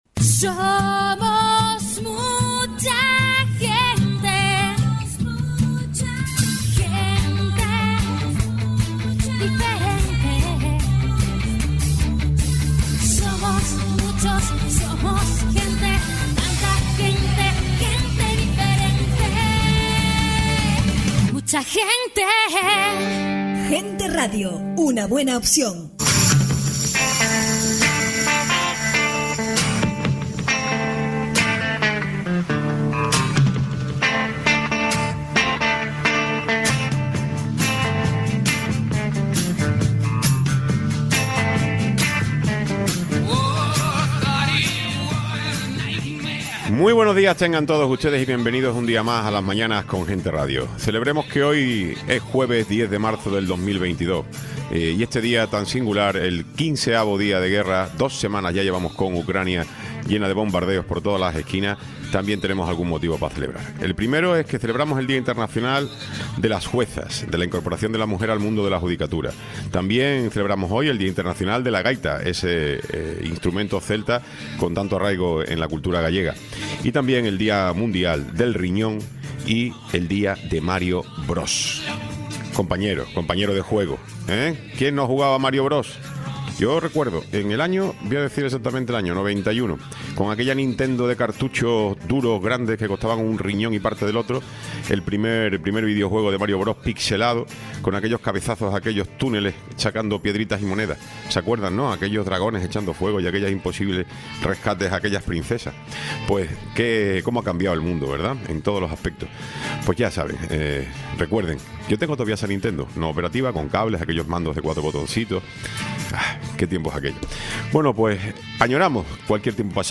Tiempo de entrevista
Tiempo de entrevista con Enrique Arriaga, Vicepresidente del Cabildo de Tenerife